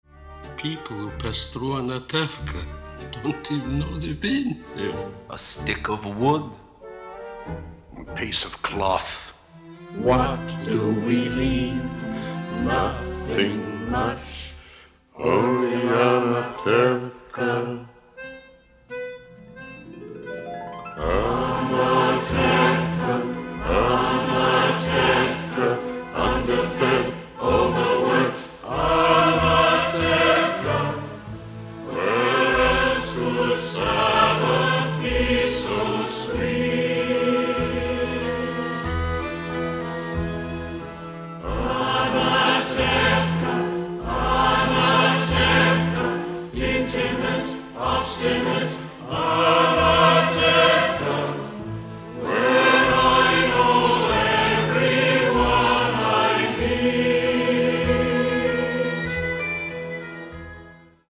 virtuoso violin soloist